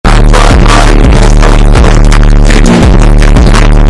7 übersteuert